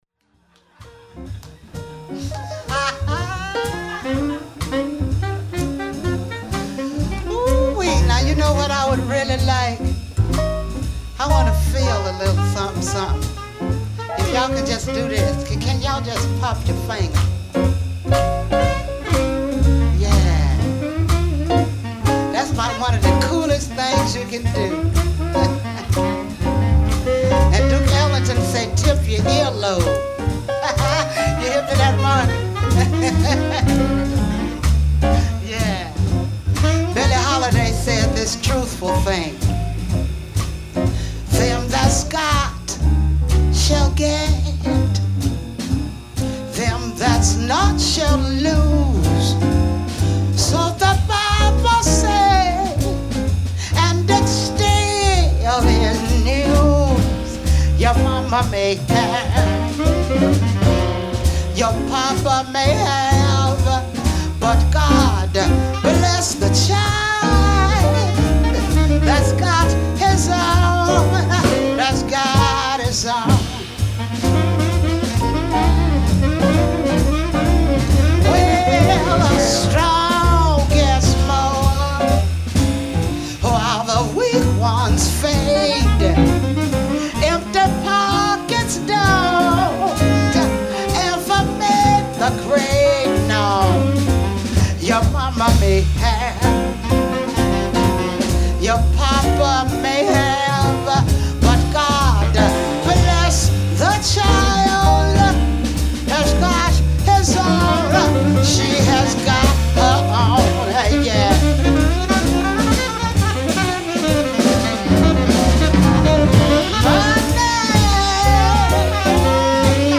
Jazz
bass
drums
piano
saxophone , vocals